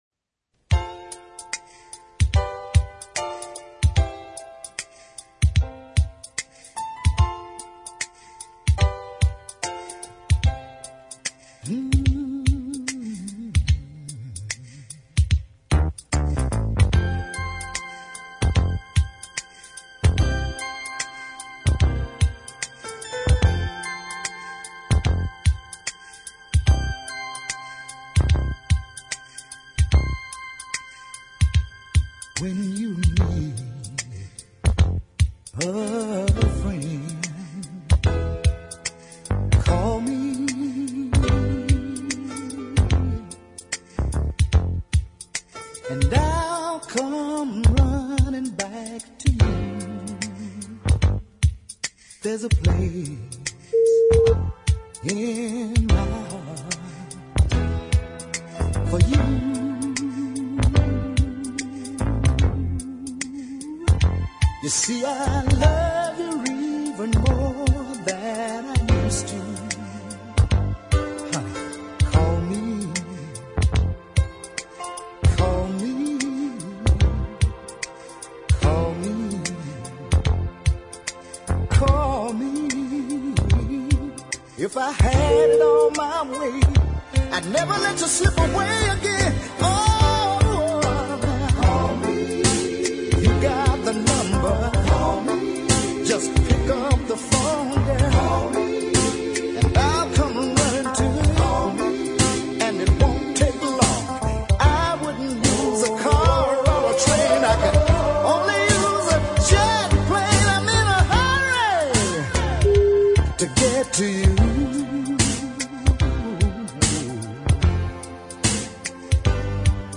with their memorably tuneful hooks